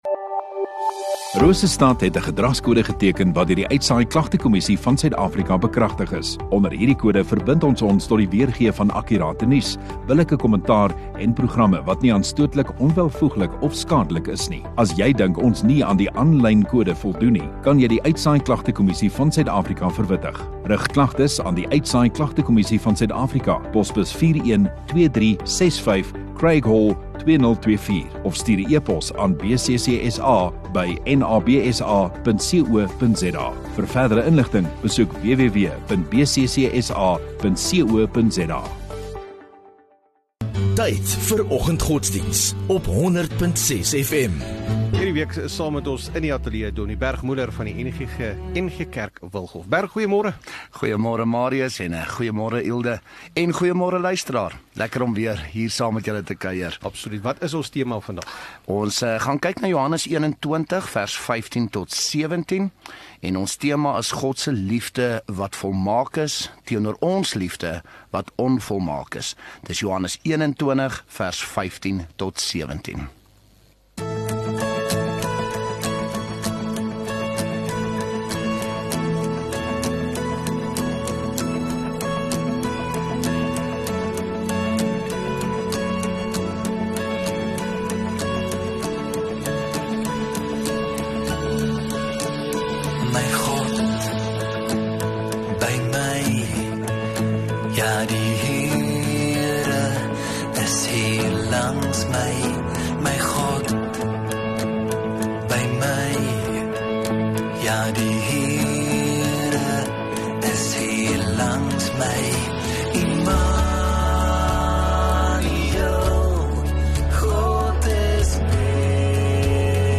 16 Sep Maandag Oggenddiens